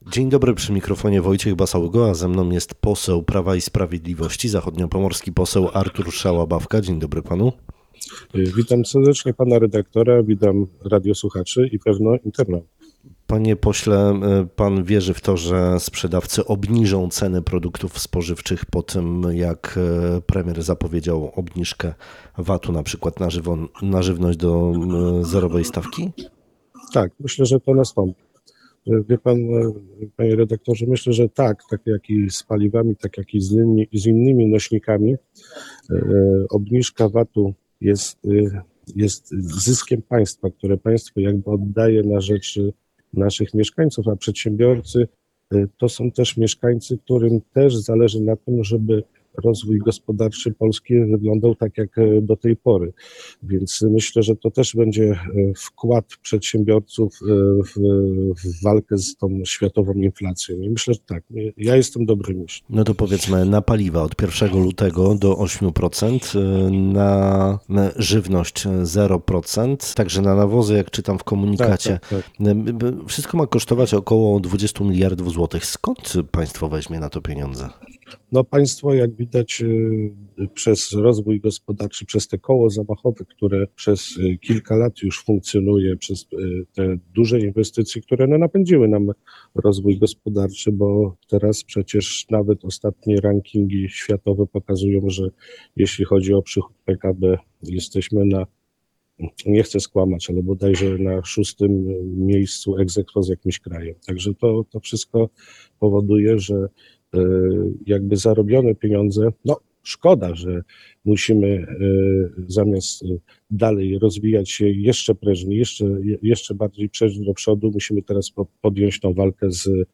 Naszym dzisiejszym gościem w Rozmowie Dnia jest pan Artur Szałabawka, zachodniopomorski poseł Prawa i Sprawiedliwości. Zadaliśmy pytania między innymi odnośnie obniżce VAT na paliwo, poruszyliśmy również temat „Polskiego Ładu”.